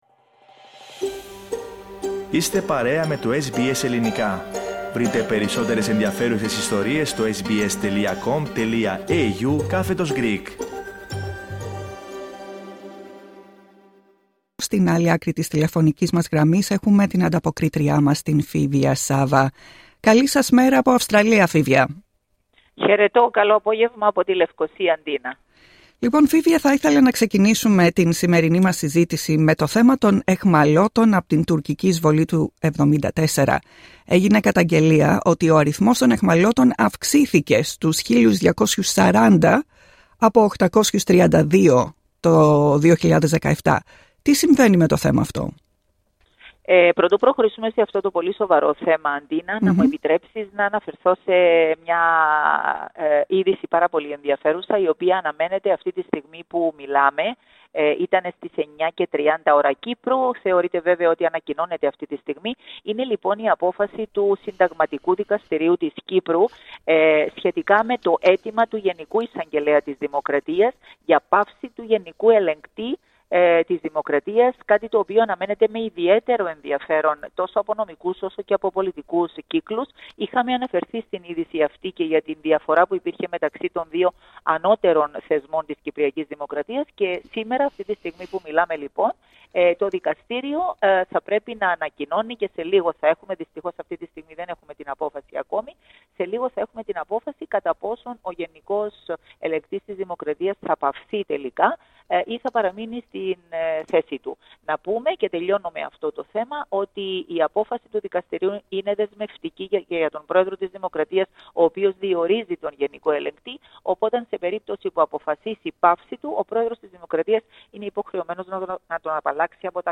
Ακούστε την εβδομαδιαία ανταπόκριση από την Κύπρο